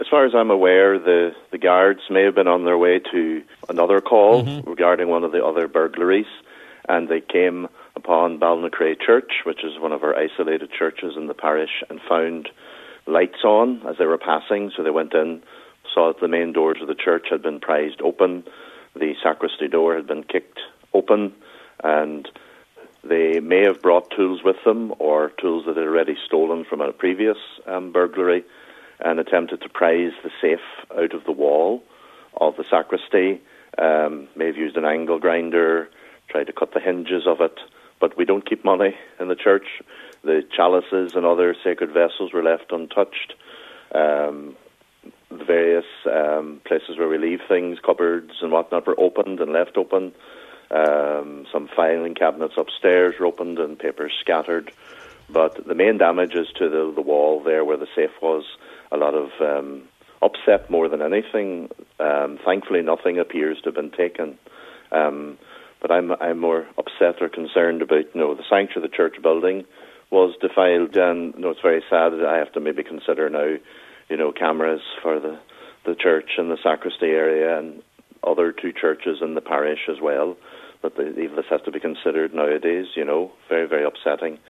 told today’s Nine til Noon Show that it was a very upsetting incident: